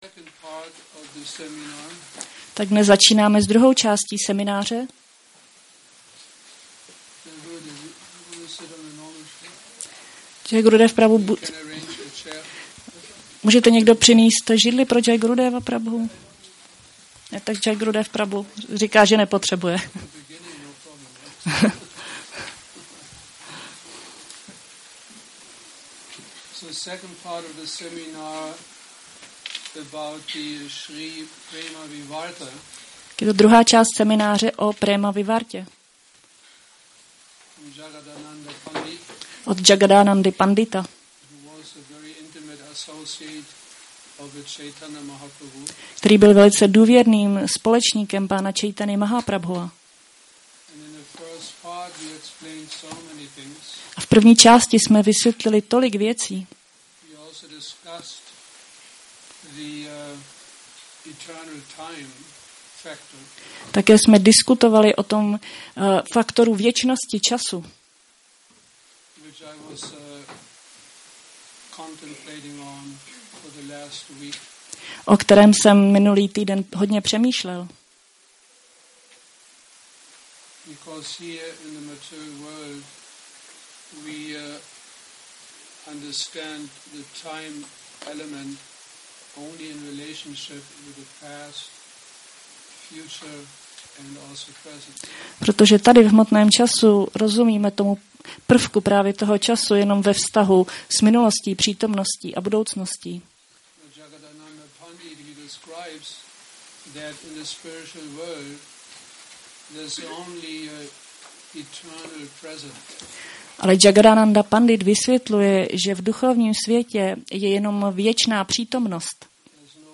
Seminář Prema Vivarta 02 – Šrí Šrí Nitái Navadvípačandra mandir